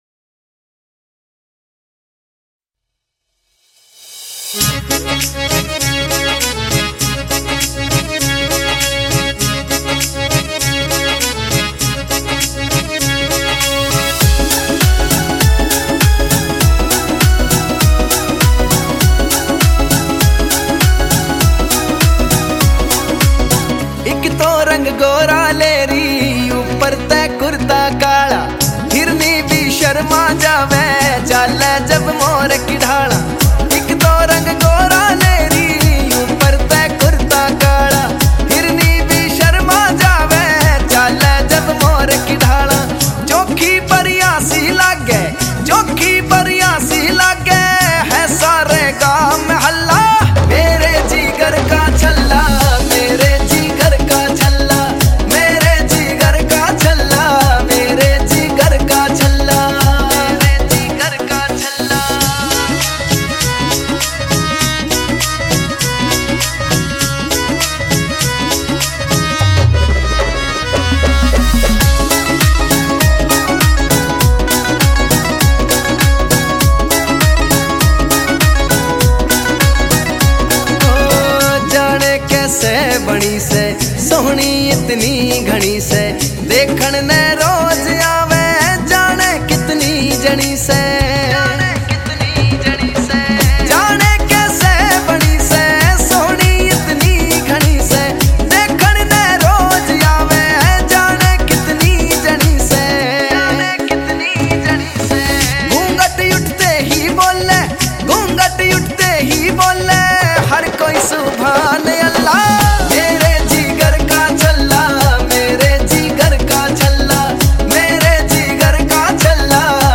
New Haryanvi